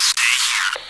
radio_stay.wav